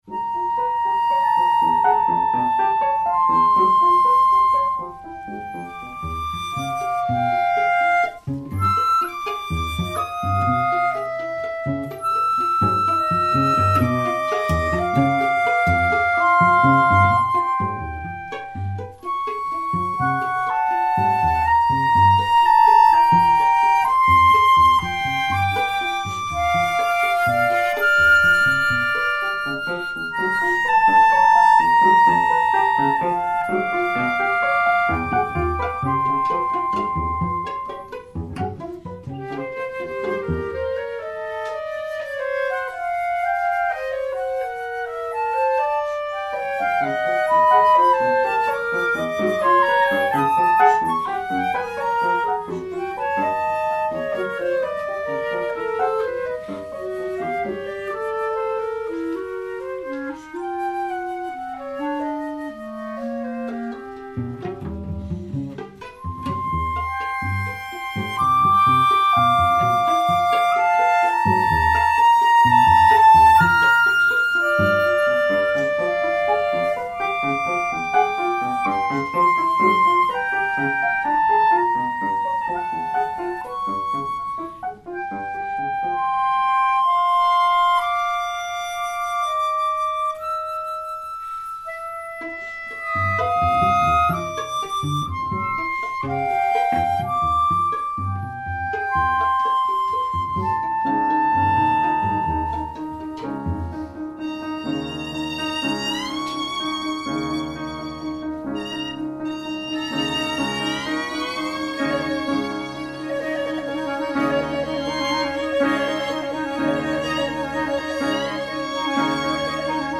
(1988), for flute, clarinet, violin, cello, and piano. 4 minutes.
At the beginning, a canon between the flute and the clarinet is presented against a percussive background of short piano notes and plucked strings. After a solo violin interlude, the canon is repeated (in reverse) with a pedal-point ostinato added to its surroundings. The slow-moving, mechanical canon is also put into perspective by the more active, expressive violin solo.
Both showed strong lyric and rhythmic development.